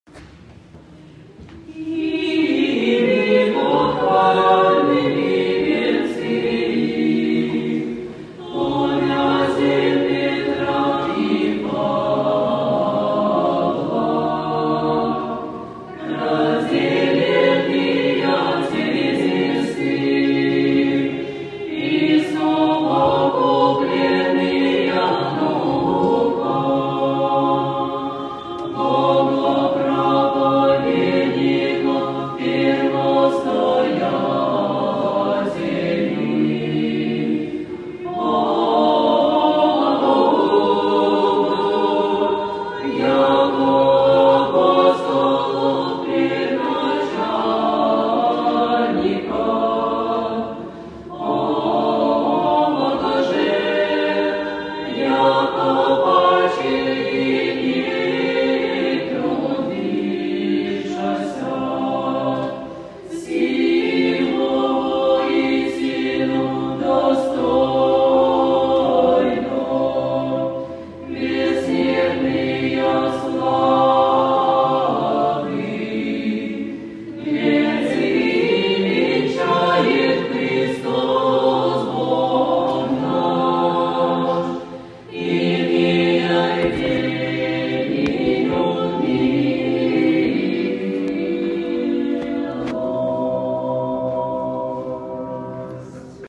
В канун праздника св. Первоверховных апостолов Петра и Павла в нашей обители совершено всенощное бдение | Богородице-Рождественский ставропигиальный женский монастырь